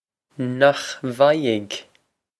Nokh vie-g?
This is an approximate phonetic pronunciation of the phrase.